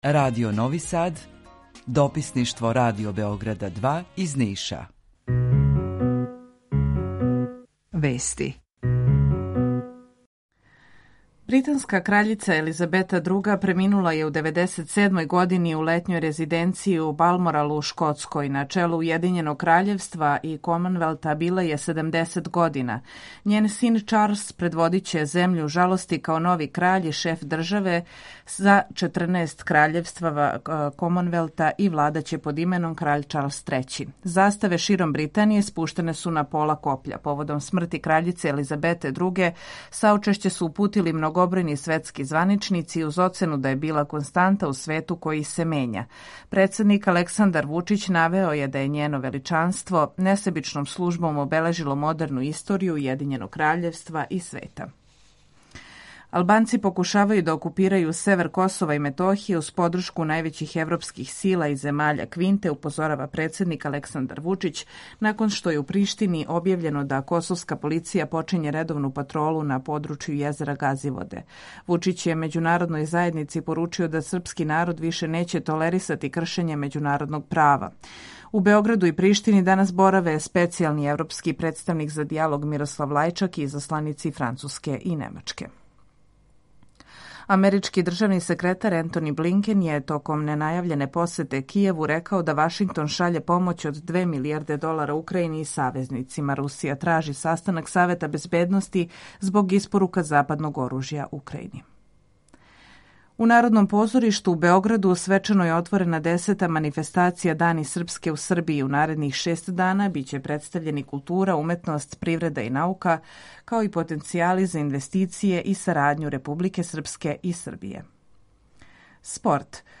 Емисију реализујемо заједно са студиом Радија Републике Српске у Бањалуци и са Радио Новим Садом.
У два сата, ту је и добра музика, другачија у односу на остале радио-станице.